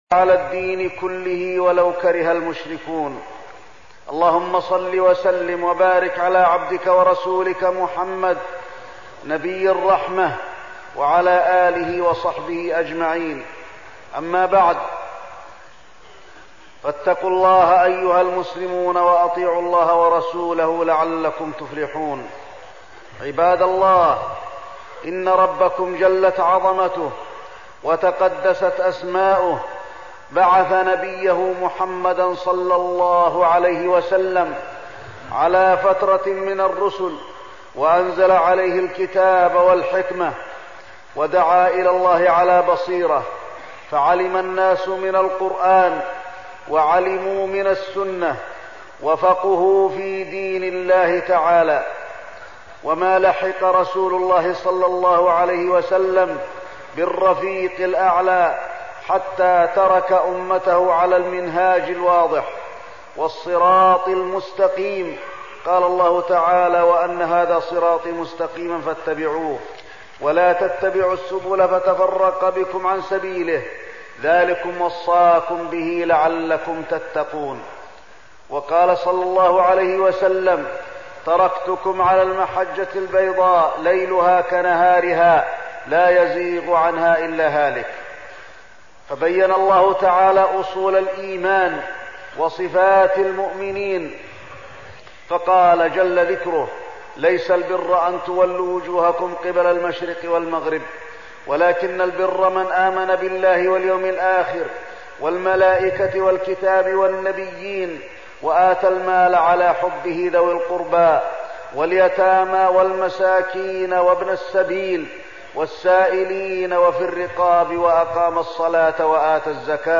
تاريخ النشر ٢٥ رجب ١٤١٧ هـ المكان: المسجد النبوي الشيخ: فضيلة الشيخ د. علي بن عبدالرحمن الحذيفي فضيلة الشيخ د. علي بن عبدالرحمن الحذيفي صفات الفرقة الناجية